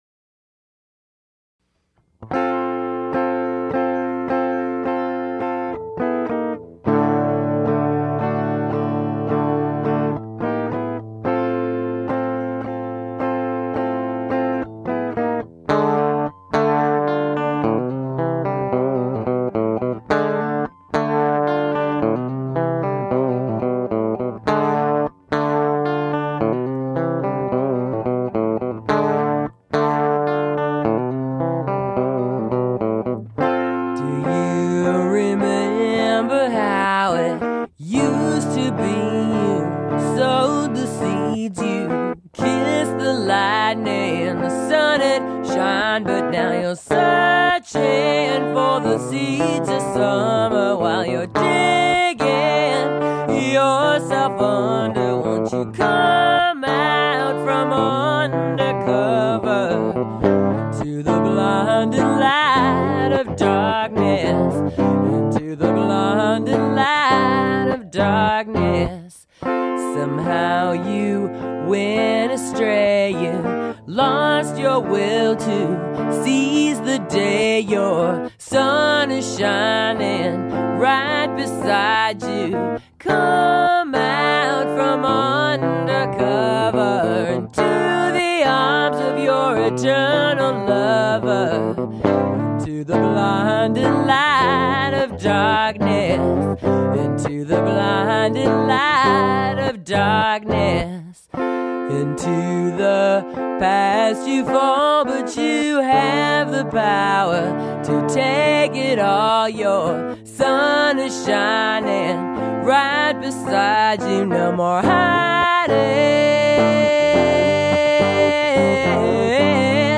This is recorded in Seattle with my guitar player